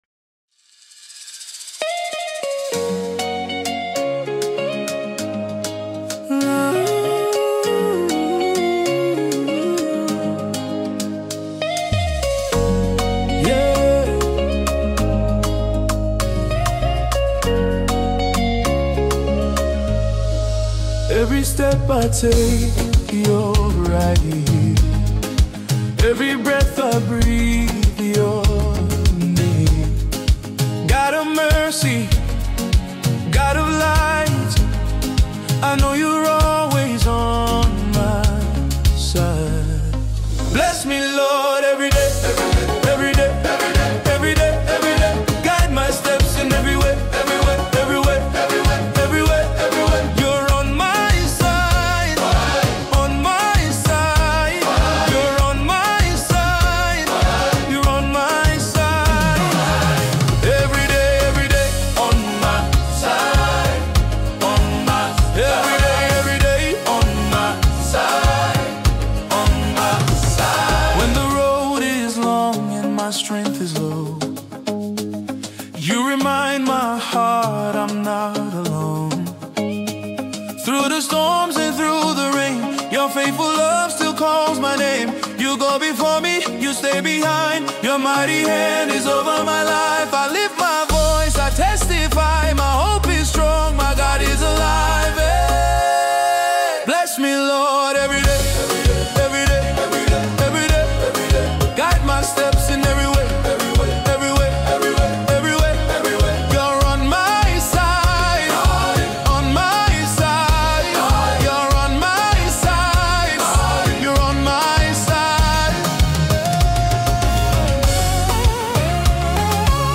gospel
inspirational song
spirit-filled gospel song